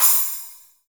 METAL SPLSH.wav